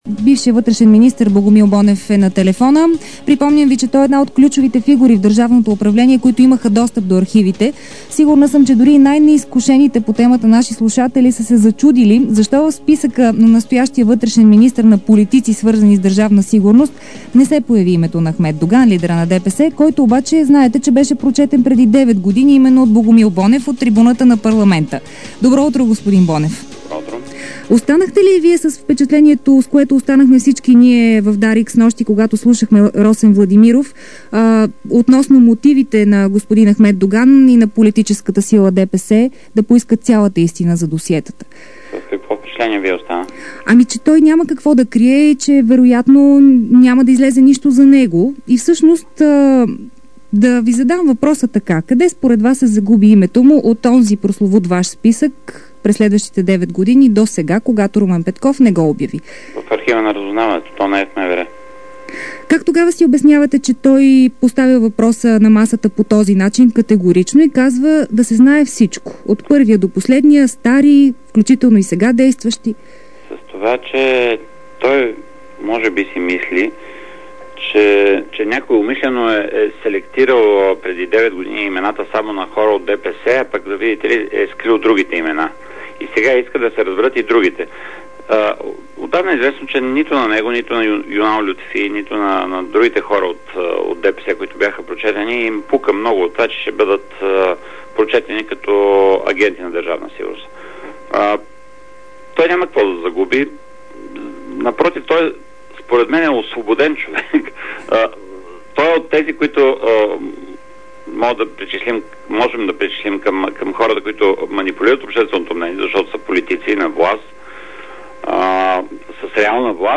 Интервю